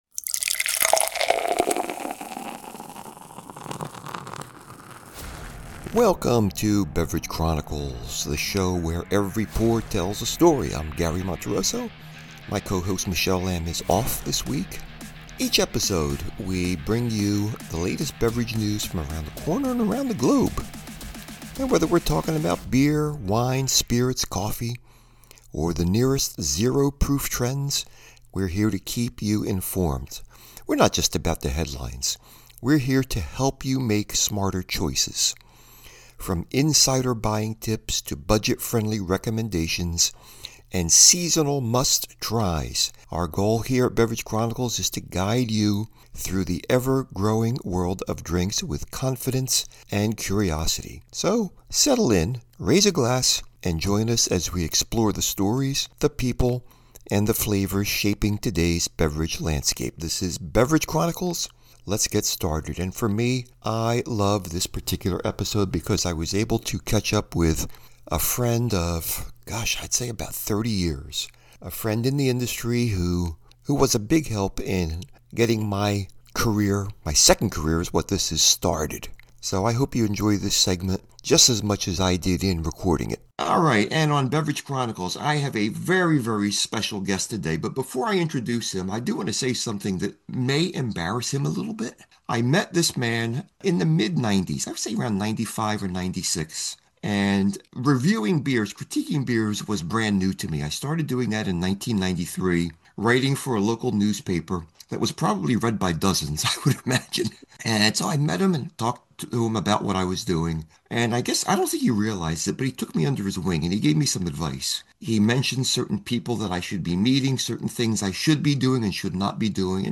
Guest, Sam Calagione, Founder of Dogfish Head Beer